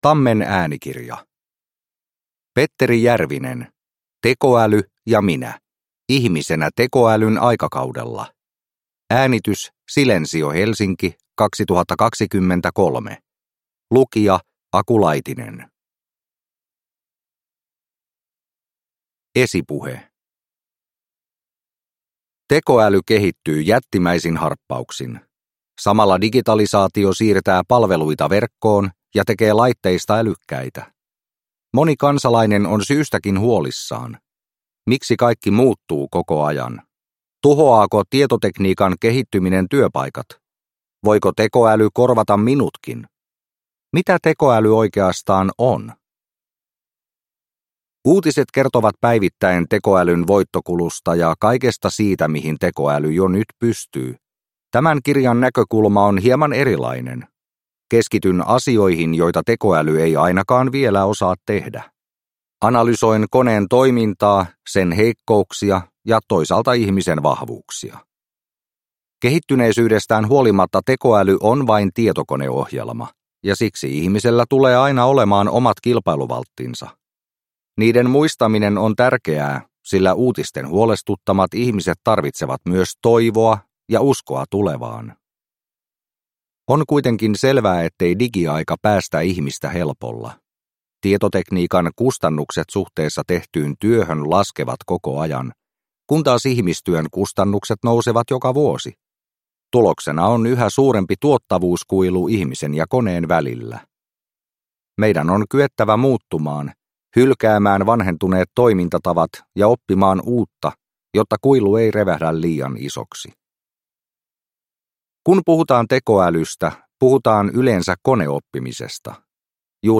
Tekoäly ja minä – Ljudbok – Laddas ner